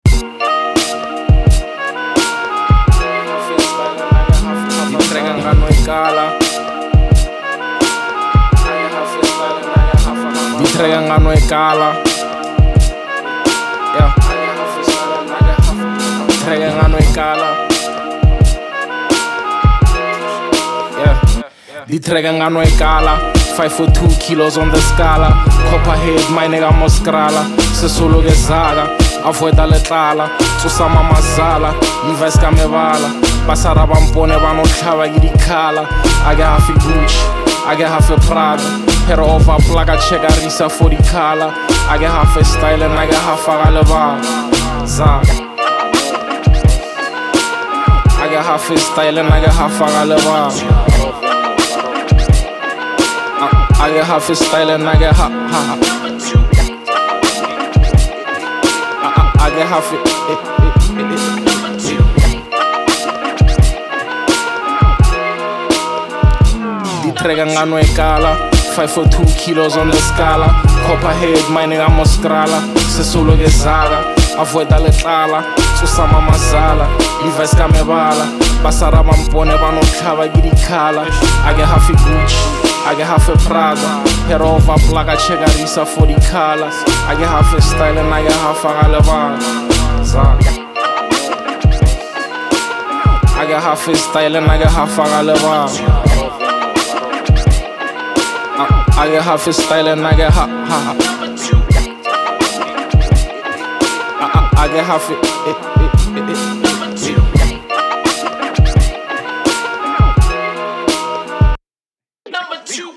South African singer-songwriter and performer